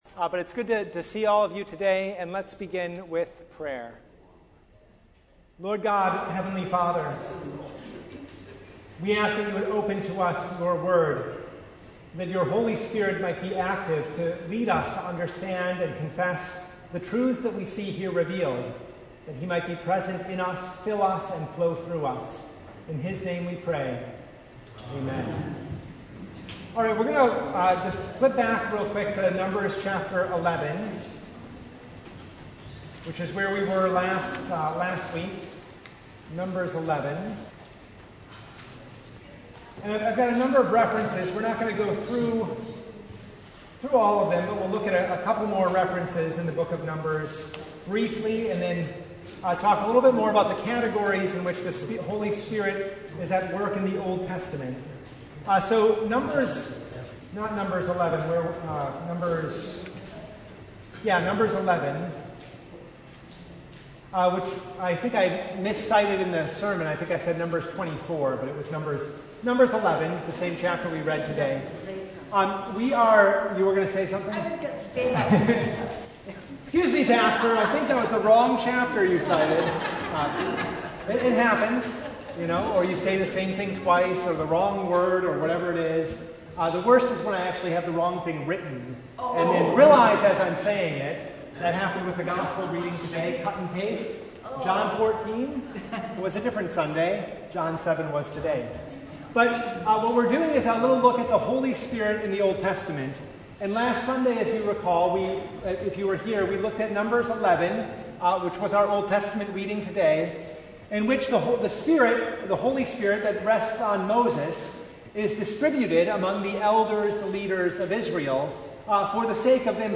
Service Type: Bible Hour Topics: Bible Study